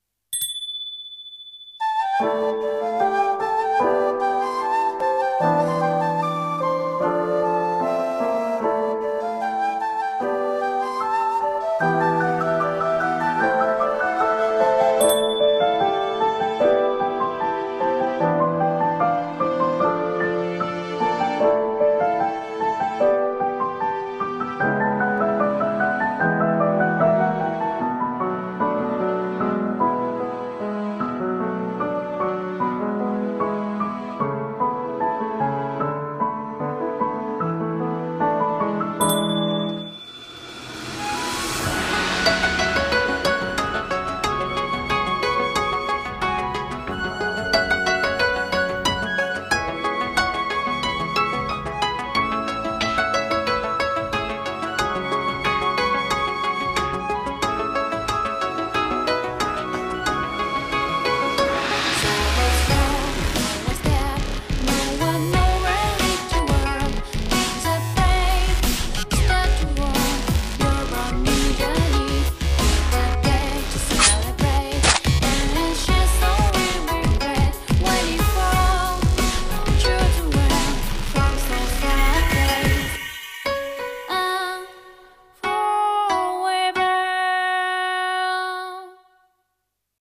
CM風声劇「狐の余命入り」